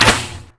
crossbow_fire.wav